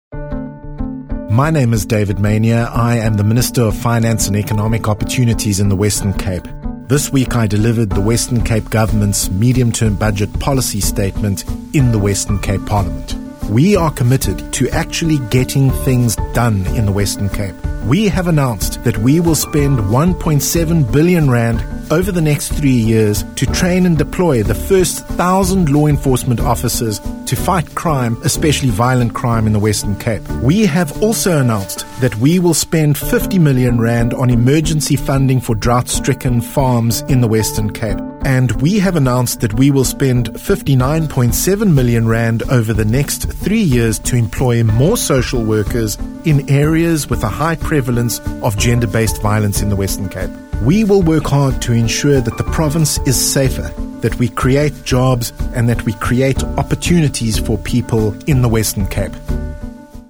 Listen to Minister Maynier's  summary of the Western Cape Government budget priorities